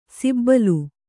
♪ sibbalu